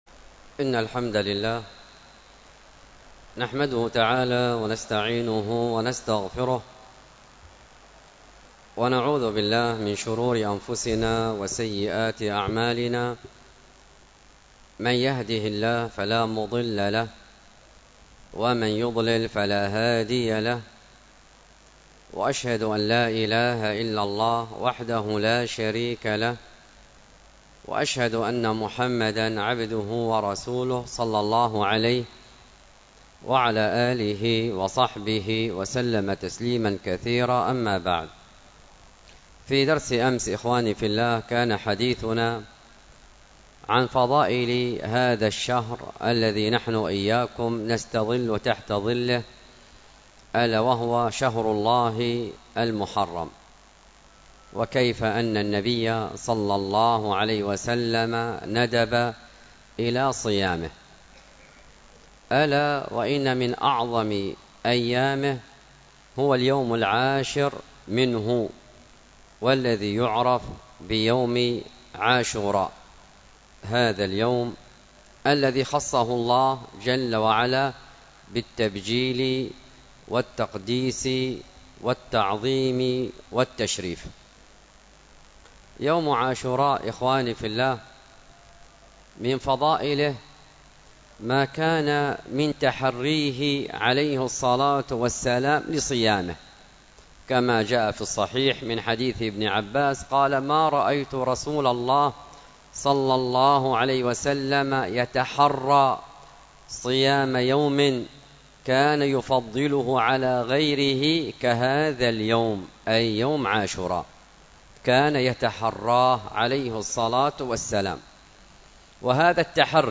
المحاضرة بعنوان فضيلة عاشوراء، والتي كانت بمسجد التقوى بدار الحديث بالشحر ظهر يوم الخميس 8 محرم 1447هـ الموافق 3 يوليو 2025م